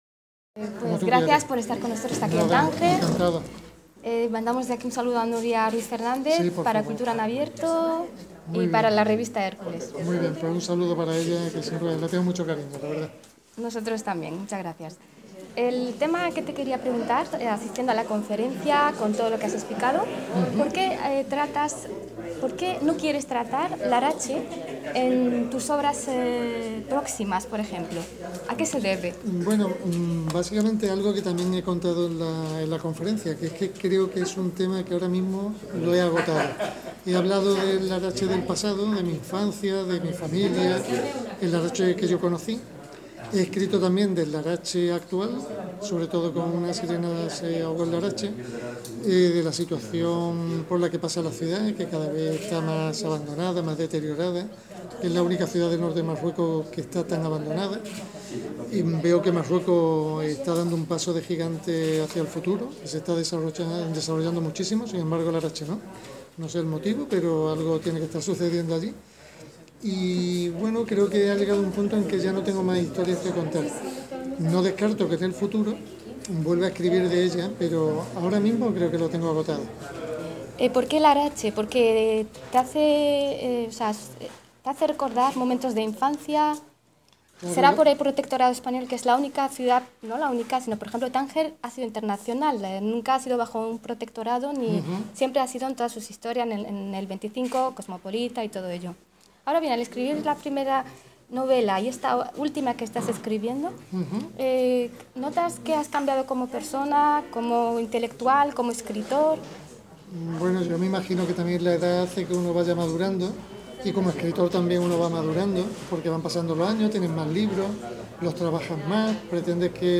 CULTURA EN ABIERTO se ha dirigido al escritor para entrevistarlo con todo el cariño y aprecio hacia su estilo y elegancia en el lenguaje durante el acto, dándole las gracias por otorgarnos su momento.